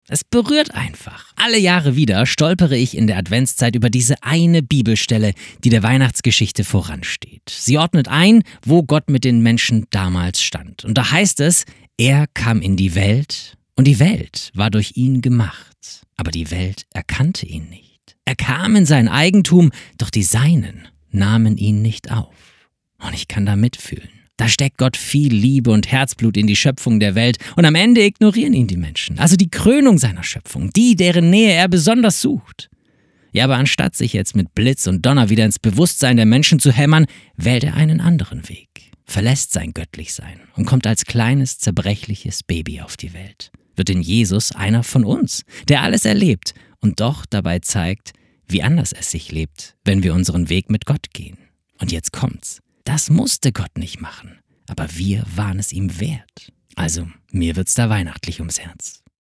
Startseite > andacht > Ich bin dann mal da